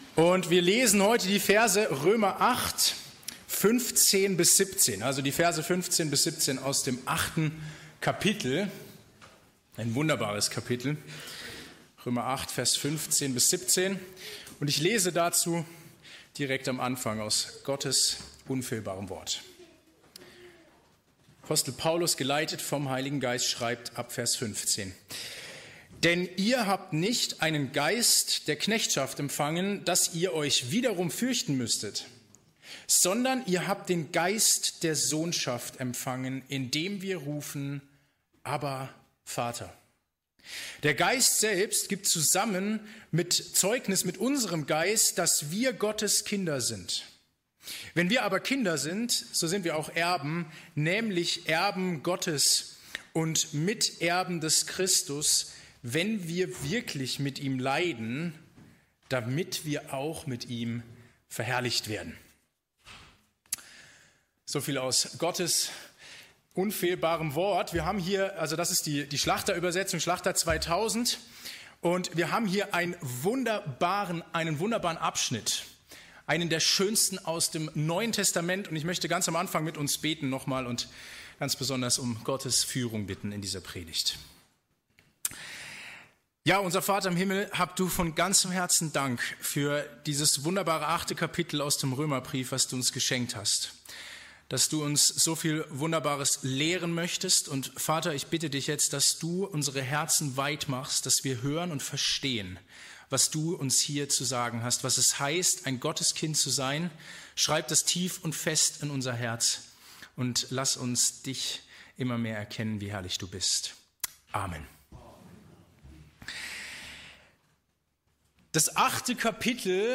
In dieser Predigt steigen wir tiefer ein in eines der gewaltigsten Kapitel der Bibel. Gemeinsam betrachten wir im Römer 8 die Verse 15 bis 17 und entdecken drei grundlegende Wahrheiten für das Leben als Christ: eine neue Identität, eine tiefe Gewissheit und eine unumstössliche Hoffnung....